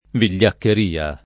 [ vil’l’akker & a ]